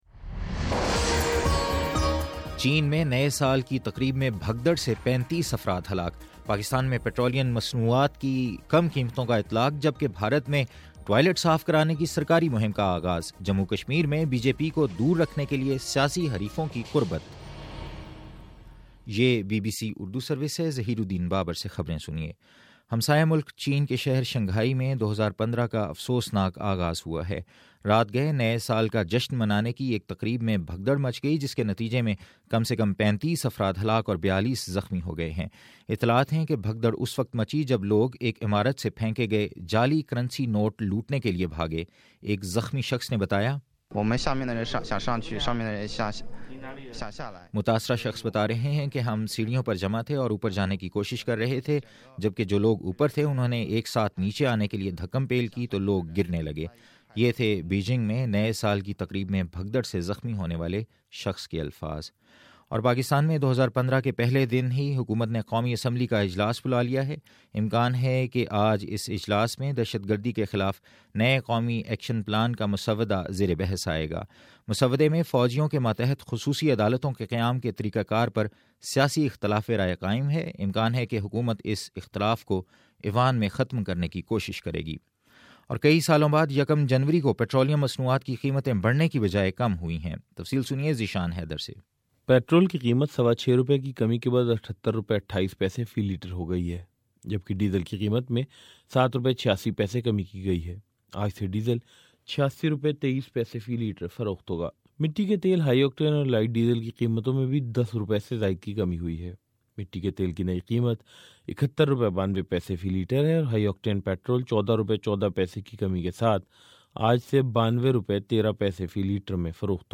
جنوری 01 : صبح نو بجے کا نیوز بُلیٹن